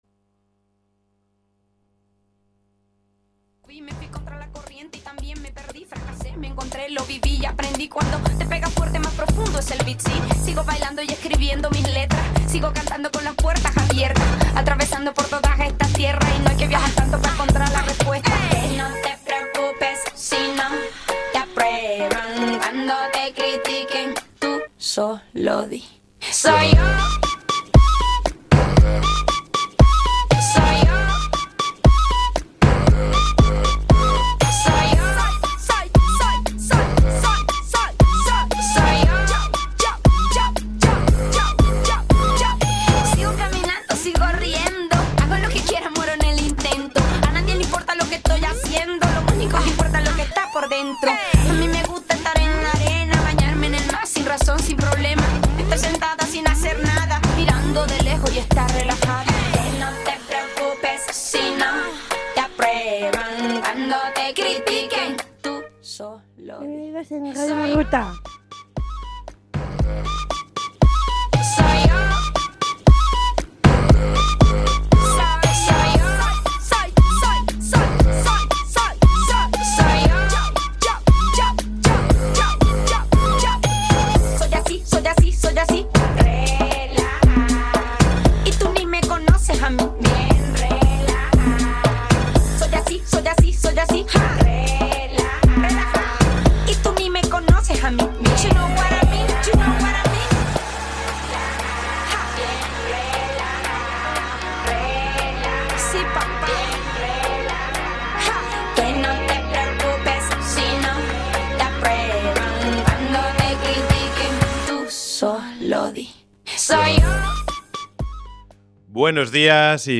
Radio en Ruta é o magazine radiofónico feito polo alumnado do programa formativo Ruta, unha formación dirixida a mozos e mozas con discapacidade intelectual da Fundación Amador de Castro.